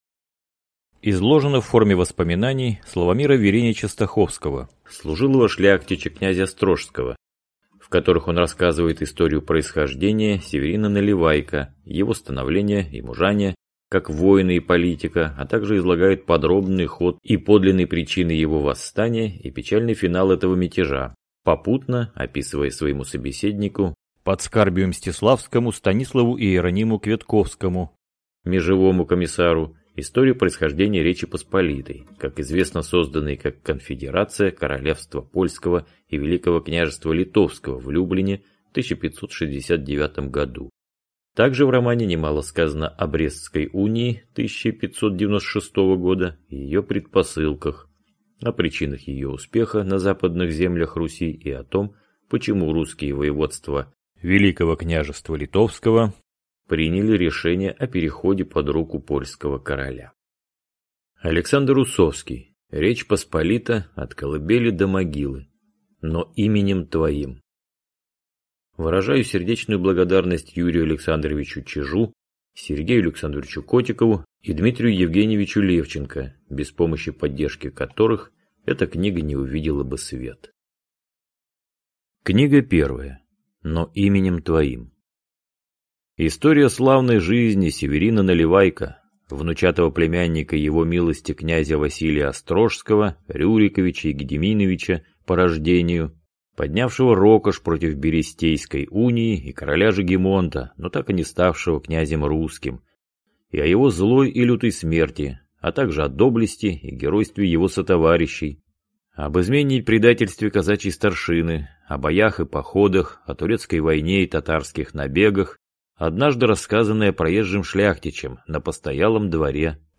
Aудиокнига Но именем твоим…